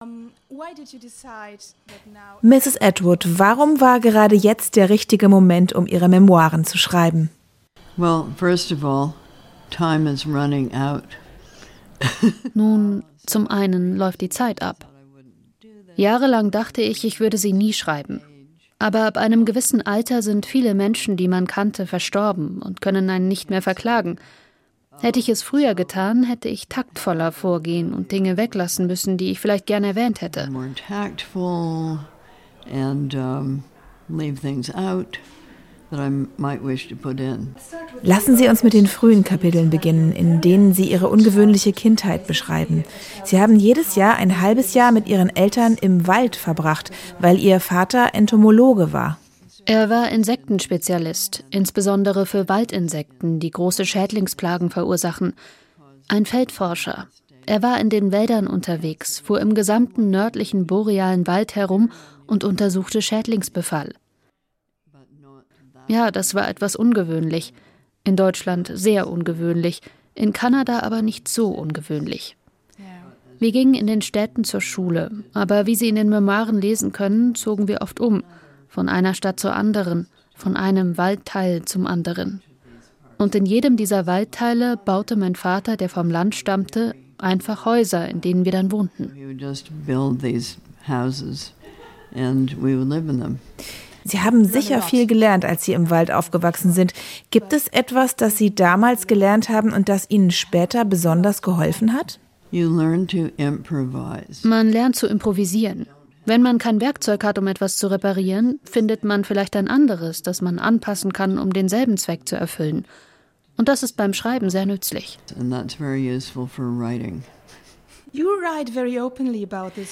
In Berlin sprach die Kultautorein über ihre Memoiren Book of Lives.